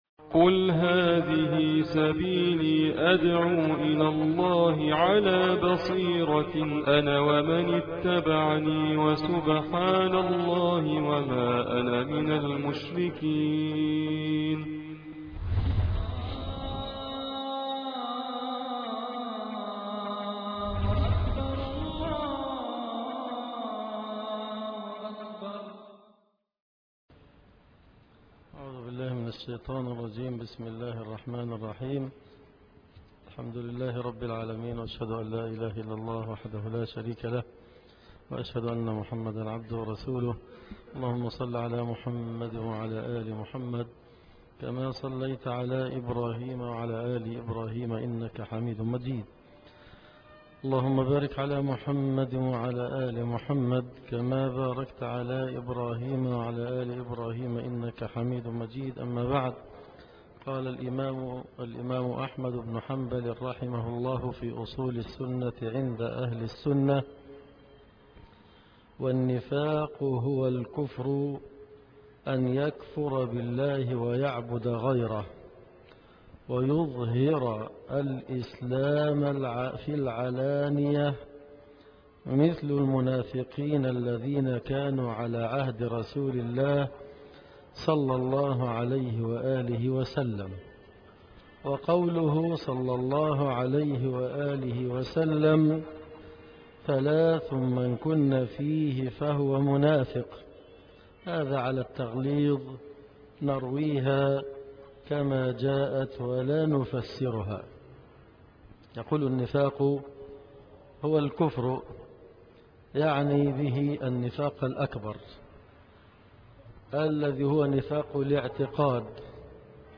الدرس ( 20) شرح أصول السنة للإمام أحمد